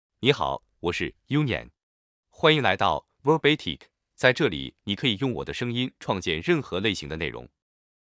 MaleChinese (Mandarin, Simplified)
YunyangMale Chinese AI voice
Yunyang is a male AI voice for Chinese (Mandarin, Simplified).
Voice sample
Yunyang delivers clear pronunciation with authentic Mandarin, Simplified Chinese intonation, making your content sound professionally produced.